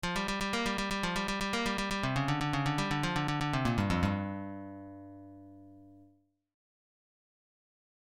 Blues lick > Lick 4